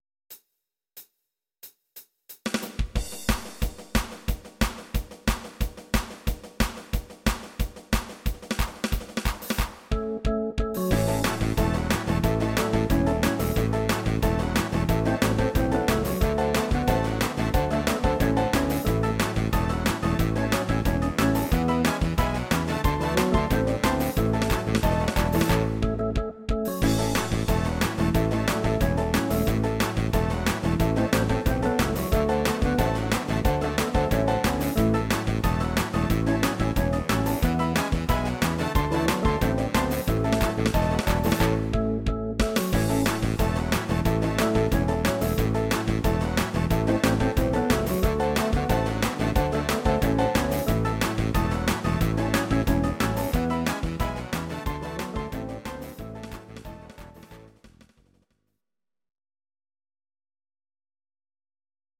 These are MP3 versions of our MIDI file catalogue.
Please note: no vocals and no karaoke included.
Your-Mix: Country (822)